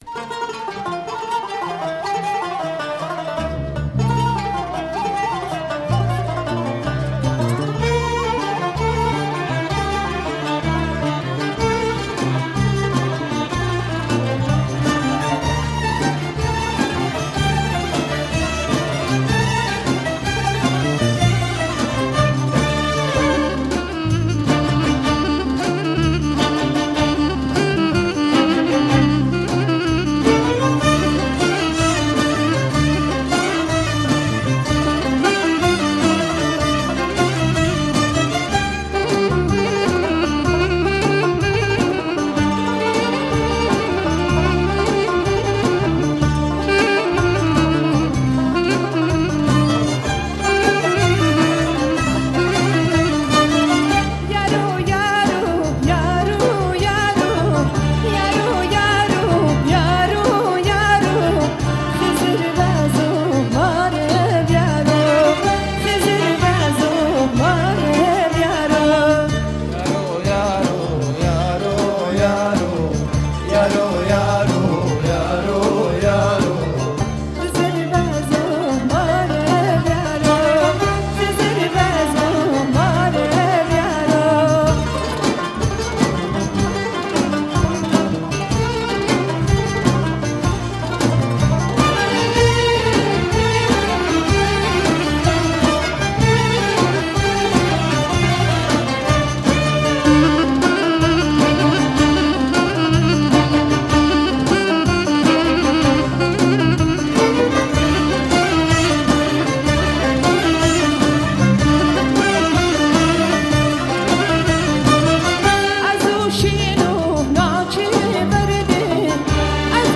آهنگ کردی جدید
آهنگ عاشقانه